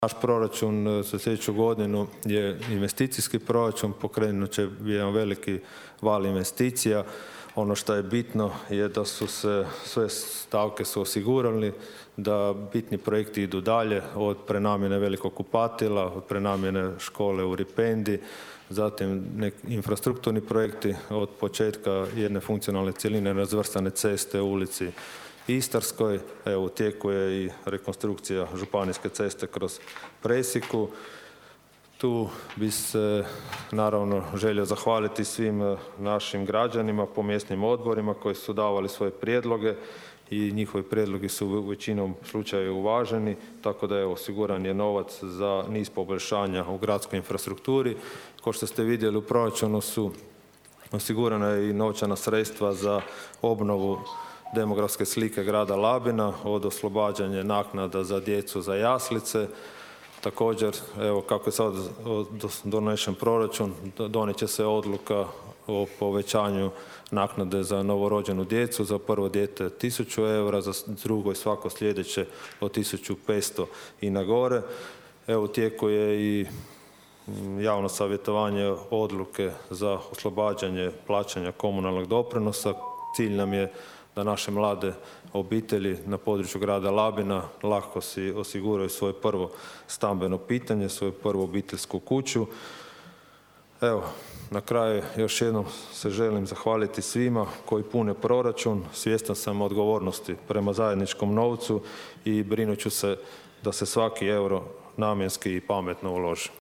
Gradonačelnik Donald Blašković zahvalio je na suradnji svim djelatnicima gradske uprave, a posebno Upravnom odjelu za gospodarstvo i EU fondove: (